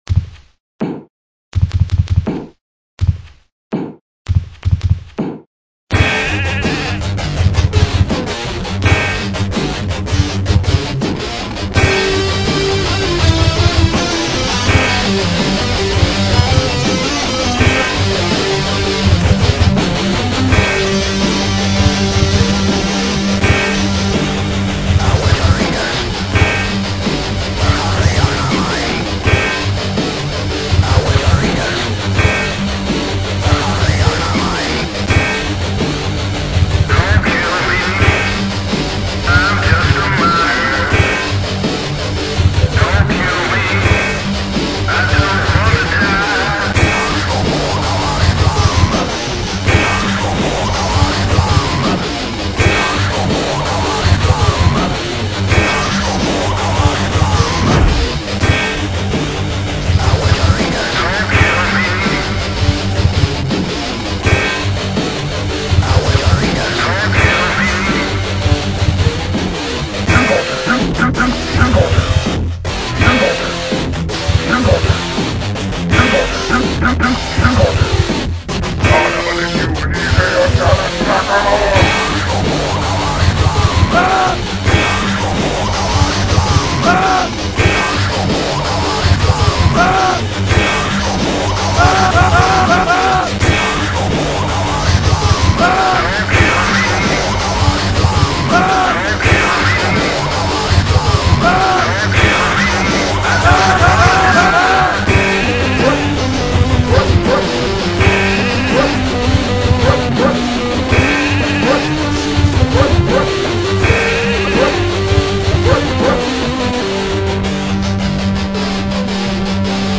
Everyone who ever mined in the early days can relate to this.  I had a better microphone this time.  But unfortunately not a better voice.
This was the first time I used actual UO sounds as the drum elements in the song.